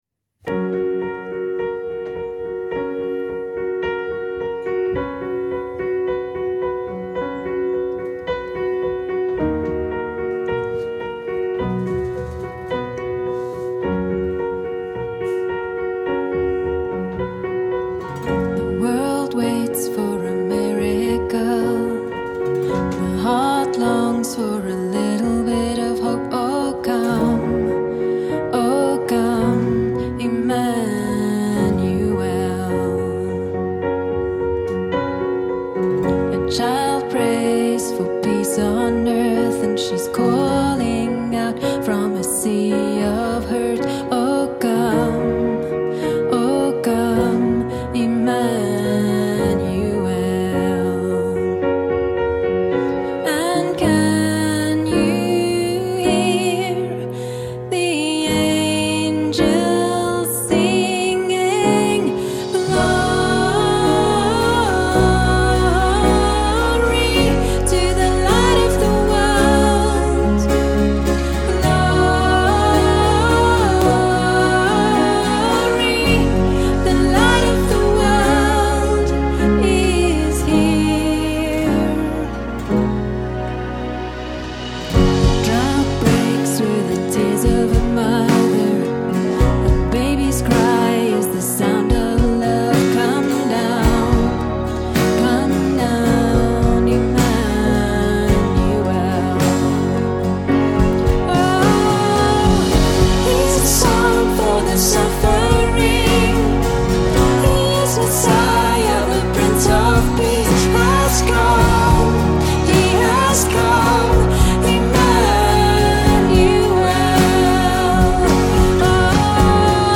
Kom luister saam na die eerste preek in ons Advent reeks